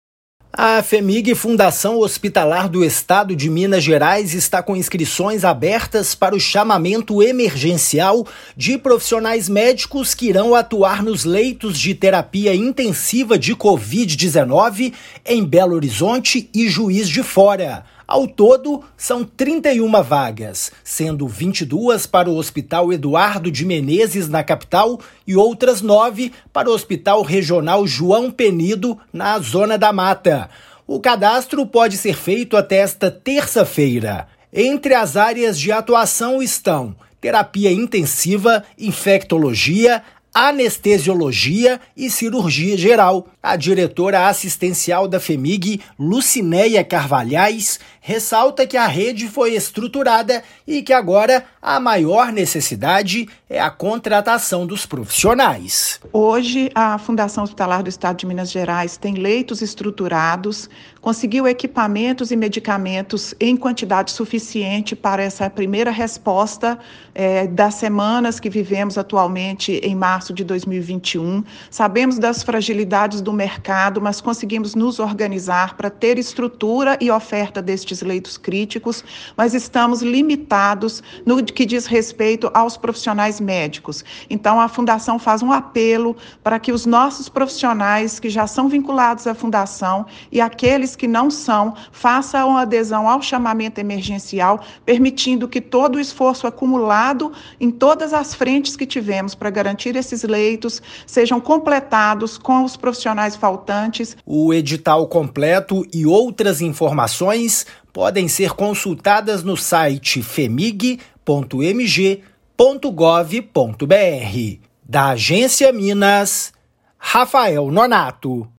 [RÁDIO] Fhemig faz chamamento emergencial para médicos em BH e Juiz de Fora
Profissionais vão atuar nos leitos de terapia intensiva de covid-19. No total, são 31 vagas temporárias para preenchimento imediato. Ouça a matéria de rádio.